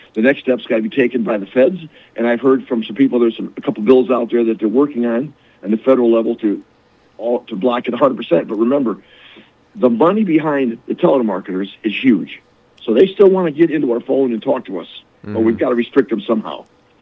During a recent radio interview, State Sen. Joe Bellino outlined what the legislation is designed to do and why lawmakers say it’s a necessary first step.